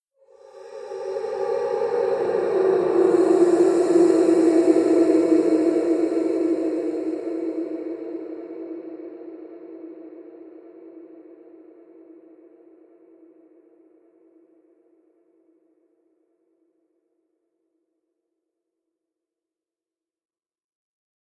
Призрак женского рода медленно зовет
prizrak_zhenskogo_roda_medlenno_zovet_75d.mp3